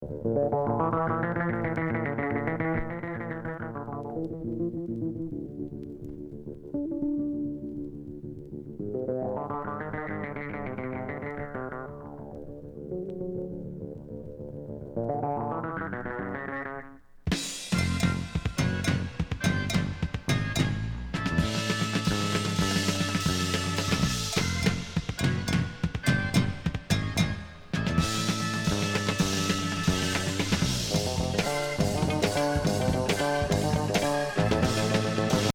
ウネウネなA3